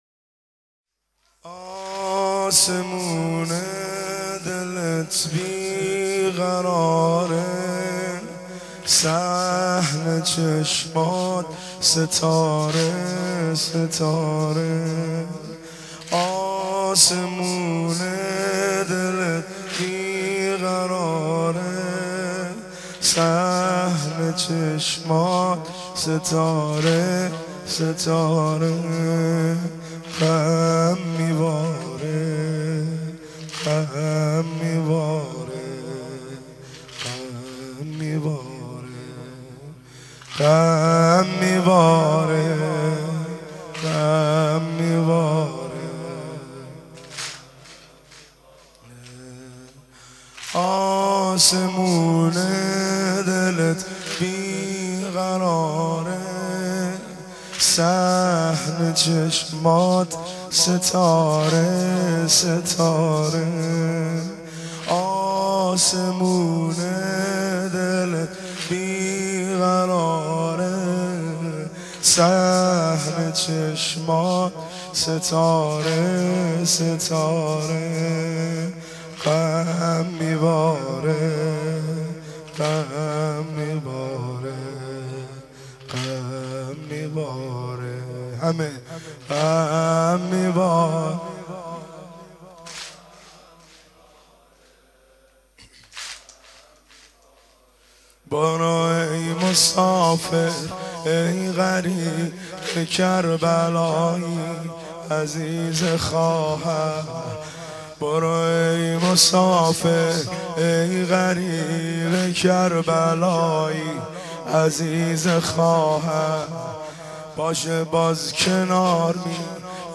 مداحی بسیار زیبا 1